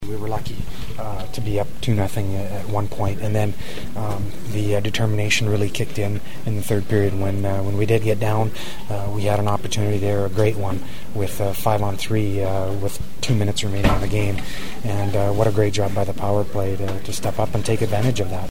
The sounds of the game from the locker room tell a story of a team that’s feeling relieved as much as joy after one of the great playoff wins in their club’s history.
Kings defenseman Robyn Regehr who described how fortunate his guys are to be in this position after knowing they were outplayed 5 on 5 much of the night: